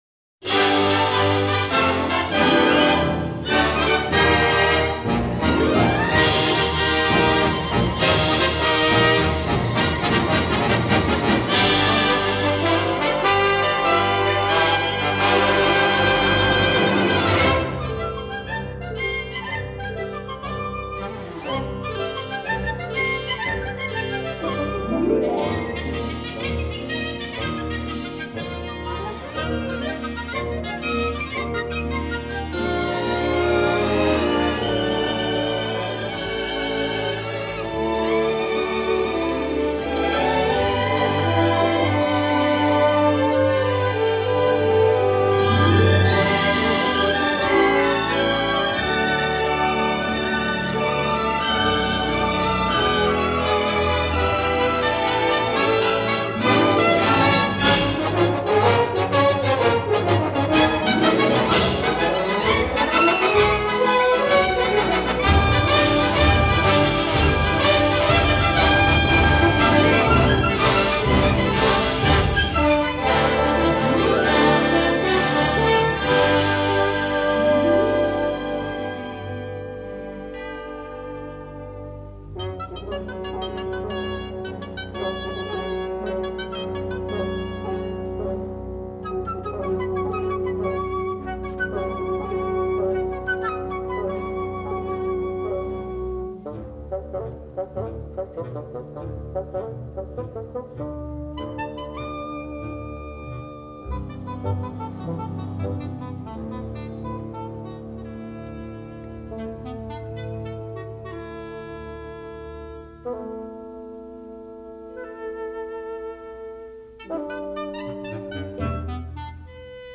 rousing Scottish-flavored score
The score gallops and swoons